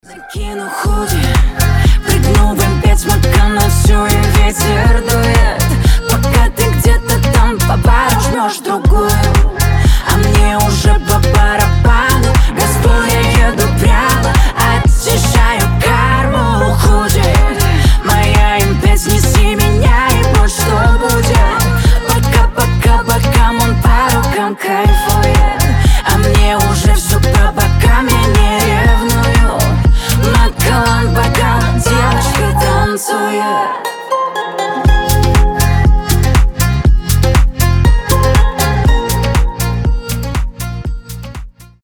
Танцевальные рингтоны
Поп